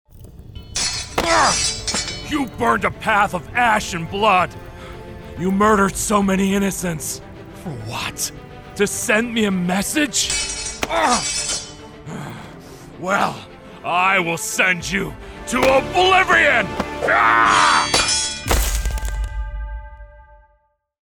Animação
When it comes to my craft, I deliver smooth articulation and a captivating tone that is heard globally.
I use a WA-47jr FET Condenser Microphone, with a Scarlett Solo interface by Focusrite. I have a pop filter, mic screen all in a treated room.